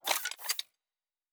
Weapon 11 Reload 2 (Rocket Launcher).wav